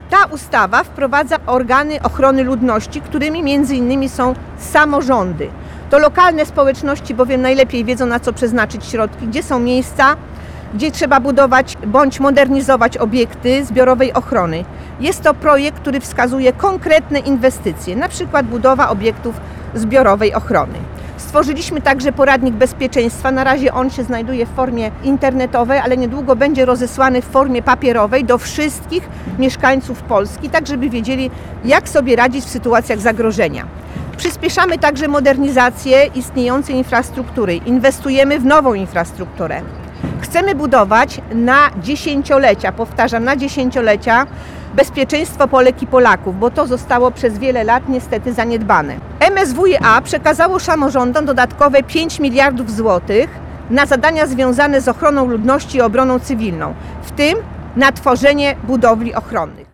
Konferencja przed płockim ratuszem
– podkreślała Elżbieta Gapińska, Posłanka na Sejm RP.